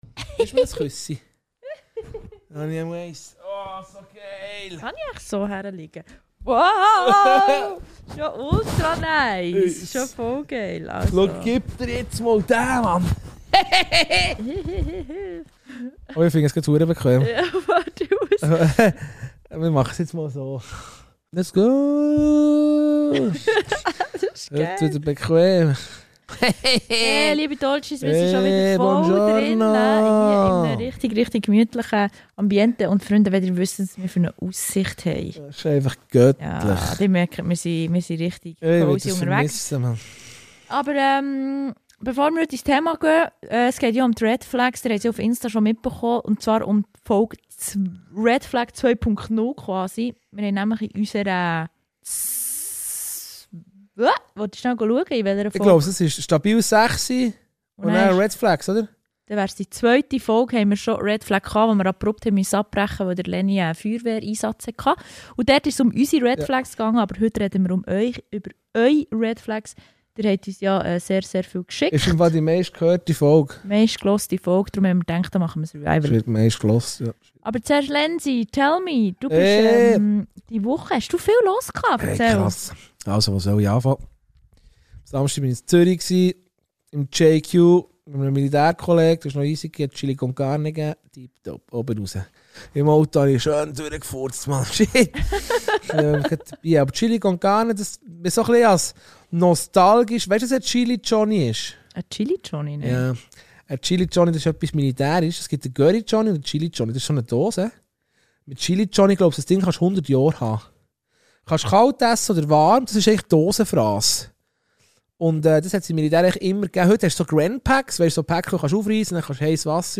Wir reden über Red Flags, die uns im Alltag begegnen – von kleinen Warnsignalen bis zu den ganz offensichtlichen . Natürlich bleibt es nicht nur bei ernsthaften Analysen: Es wird viel gelacht, ein bisschen übertrieben und hier und da auch mal abgeschweift.